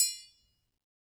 Triangle6-HitM_v1_rr2_Sum.wav